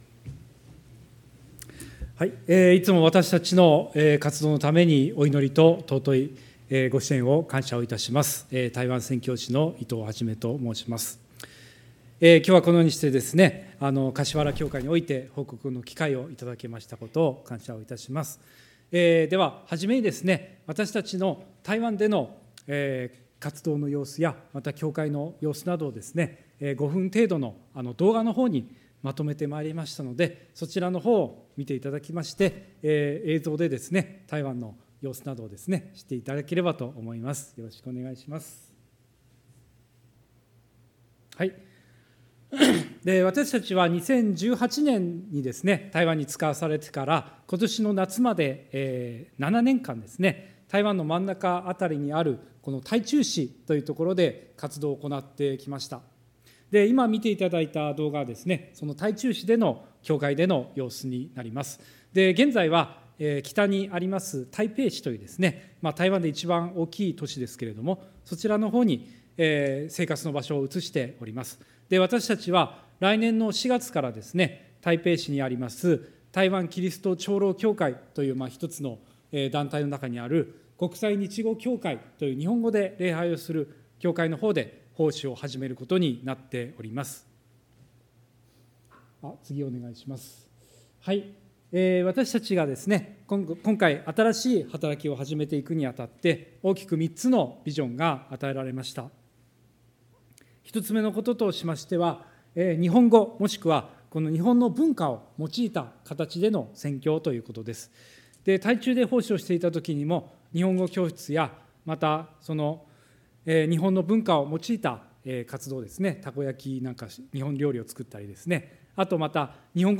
礼拝メッセージ「本当の友となる」│日本イエス・キリスト教団 柏 原 教 会